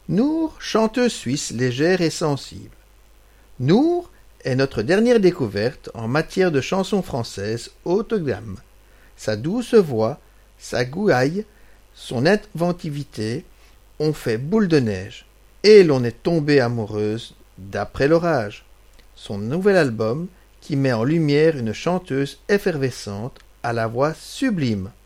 frappe par sa voix claire et chaleureuse